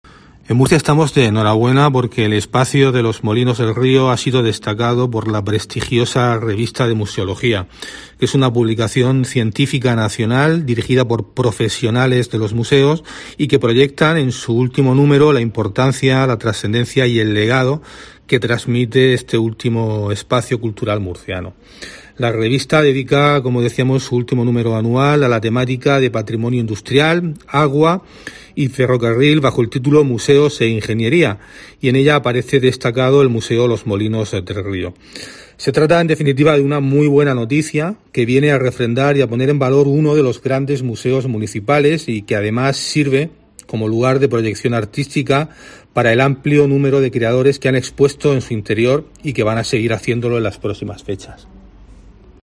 Pedro García Rex, concejal de Cultura, Turismo y Deportes